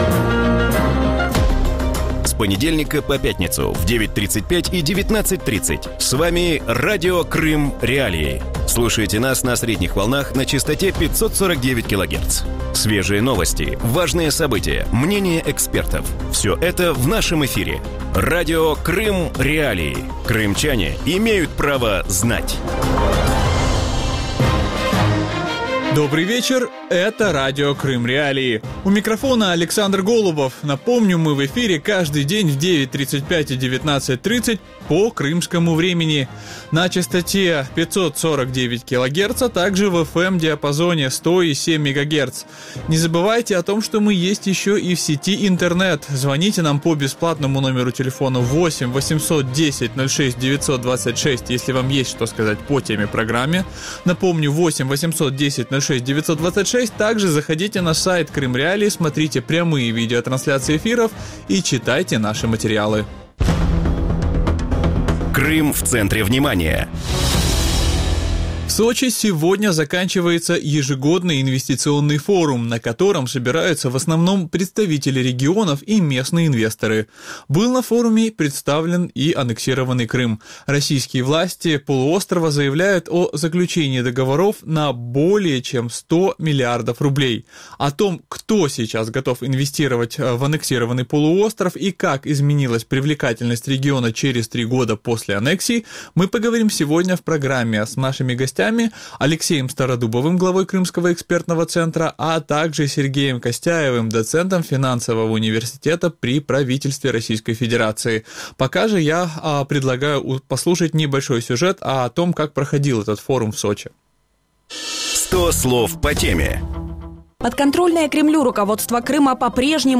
В вечернем эфире Радио Крым.Реалии говорят об инвестиционном форуме в Сочи и привлекательности Крыма для инвесторов спустя три года после аннексии. Кто сейчас не боится инвестировать в полуостров и как Крым обходится без иностранных займов?